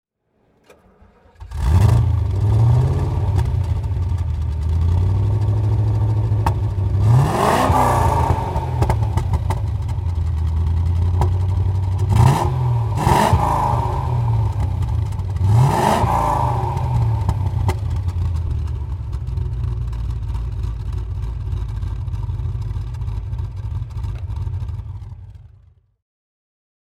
Maserati Indy (1970) - Starten und Leerlauf
Maserati_Indy_1970.mp3